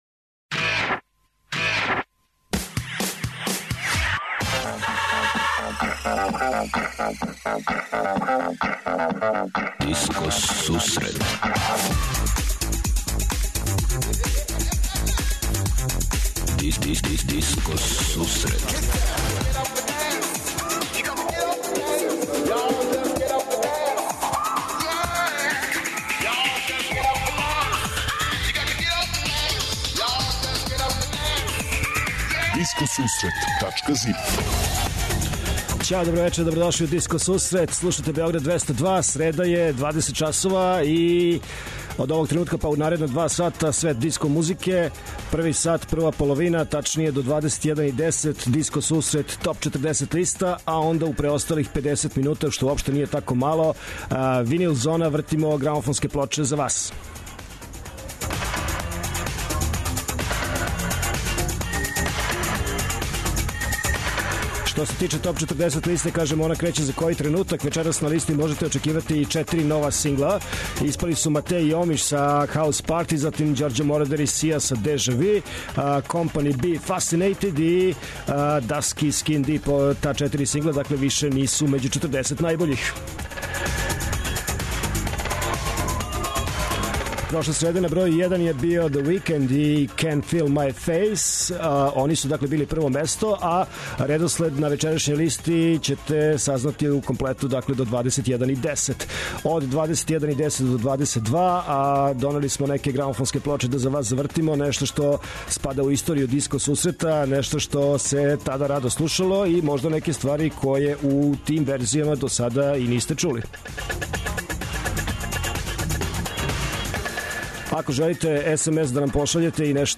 Од 20ч Диско Сусрет Топ 40 - Топ листа 40 тренутно највећих хитова који пуне диско подијуме широм света. Од 21.10ч Винил Зона: слушаоци, пријатељи и уредници Диско Сусрета за вас пуштају музику са грамофонских плоча.
преузми : 56.73 MB Discoteca+ Autor: Београд 202 Discoteca+ је емисија посвећена најновијој и оригиналној диско музици у широком смислу, укључујући све стилске утицаје других музичких праваца - фанк, соул, РнБ, итало-диско, денс, поп.